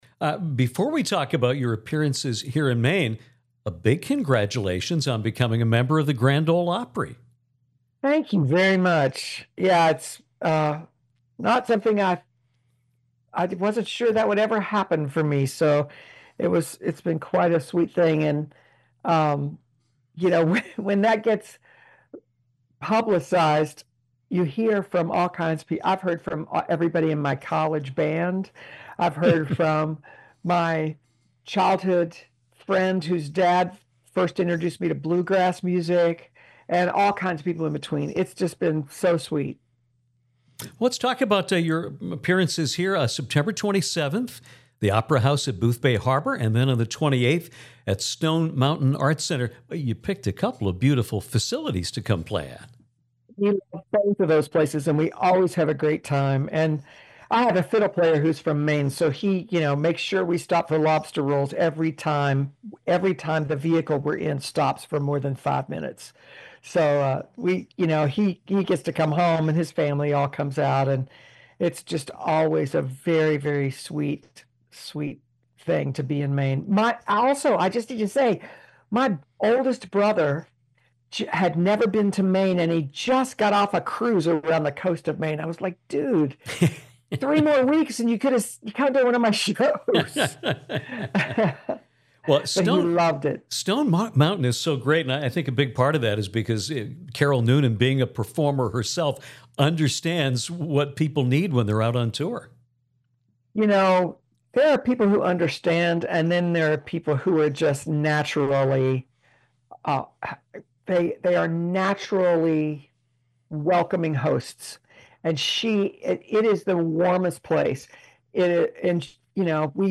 Singer Kathy Mattea returned to Downtown to talk about her upcoming Maine shows in Boothbay Harbor and Brownfield, her invitation to join the Grand Ole Opry, and her support of […]